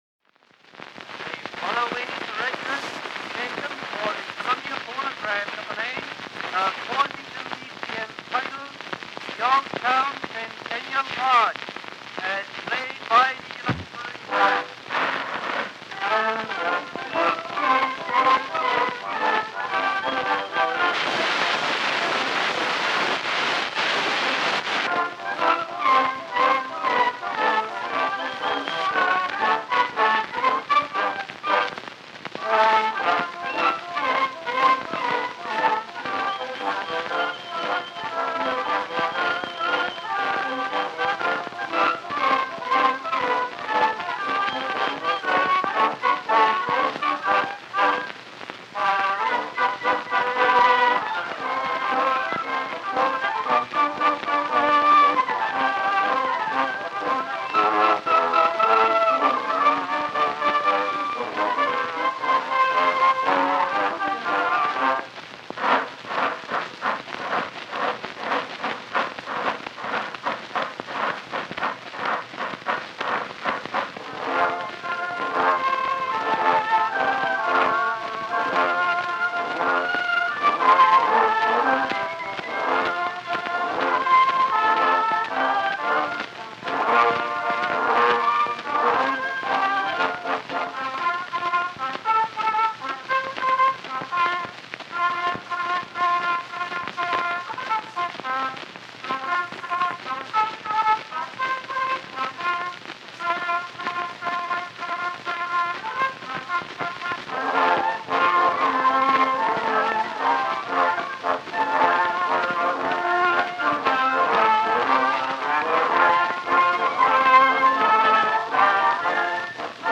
Disc 1: Early Acoustic Recordings
March